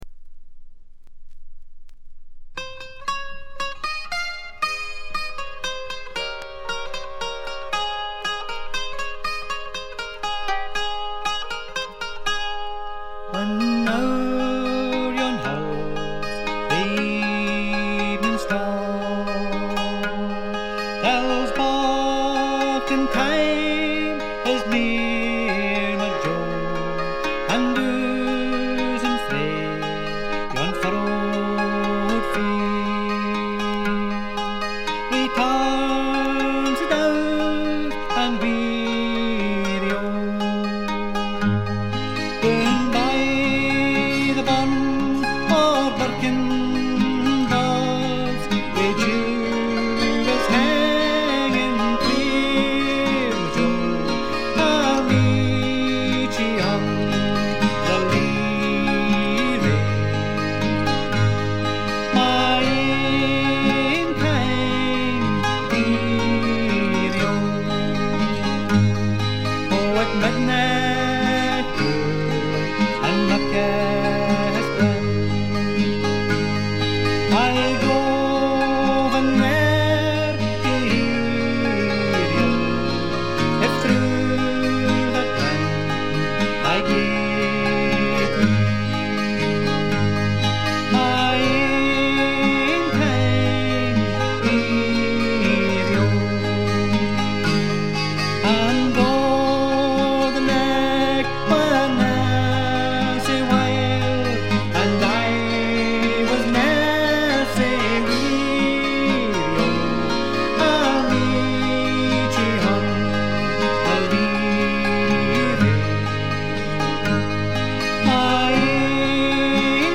わずかなチリプチ程度。
スコットランドのトラッド・グループ
ギター、笛、アコーディオン、パイプ等が織りなす美しい桃源郷のような世界が展開されます。
試聴曲は現品からの取り込み音源です。
vocals, mandola, mandoline, whistle, guitar
vocals, banjo, guitar
accordion, Highland pipes, keyboards